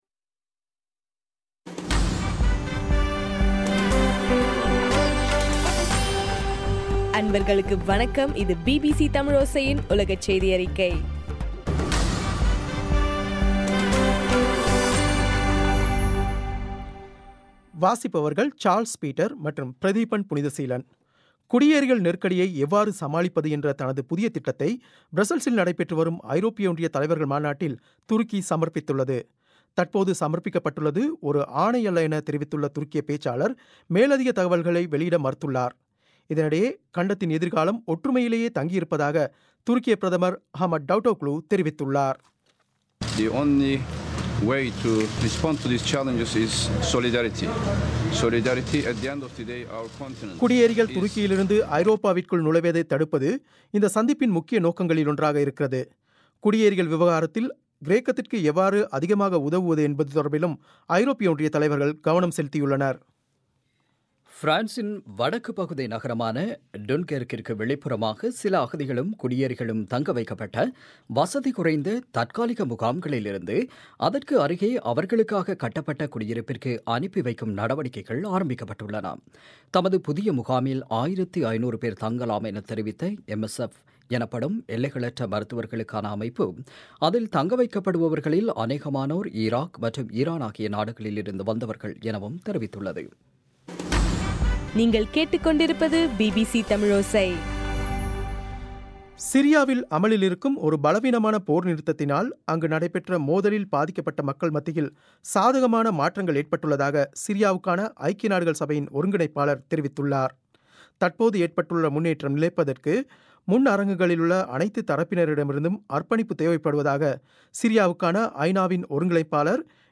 பிபிசி தமிழோசை- உலகச் செய்தியறிக்கை- மார்ச் 07